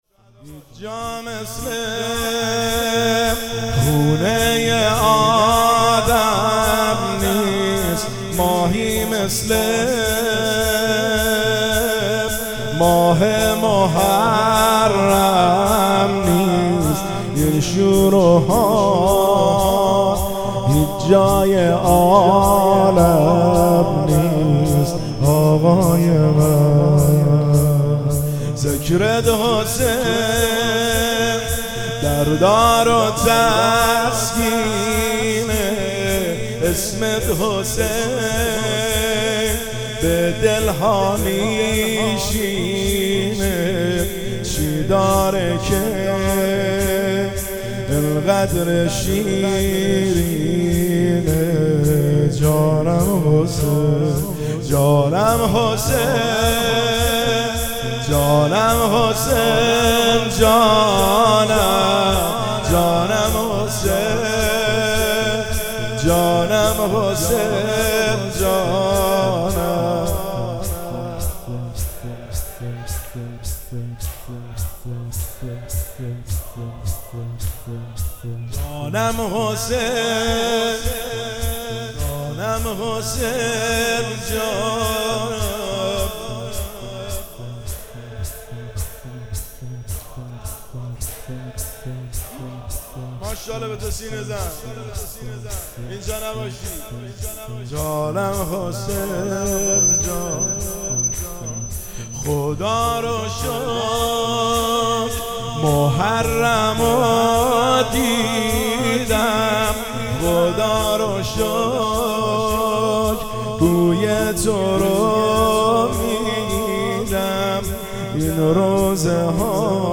شور شب ششم محرم الحرام 1402